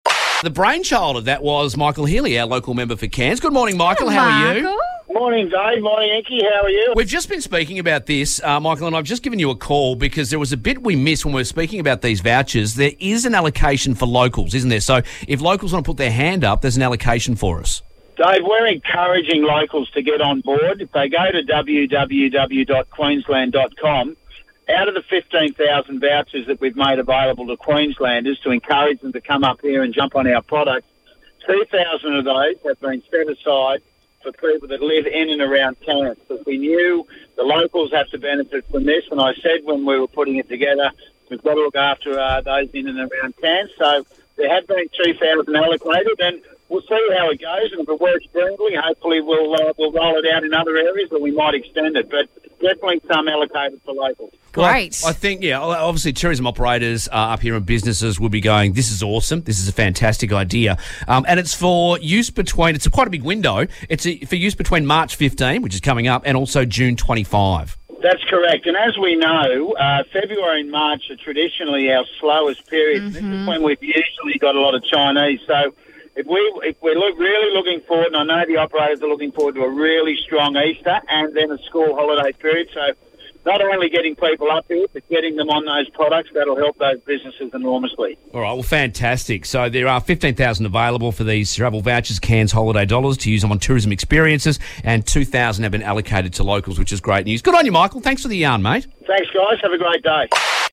We spoke with Cairns MP Michael Healy about the Cairns Holiday Dollars & how many of these 15,000 vouchers are allocated to us here in Cairns